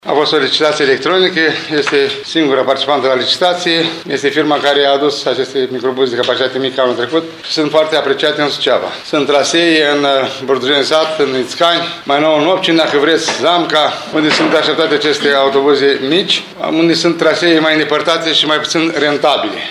Primarul ION LUNGU îți spune pe ce trasee vor circula aceste autobuze.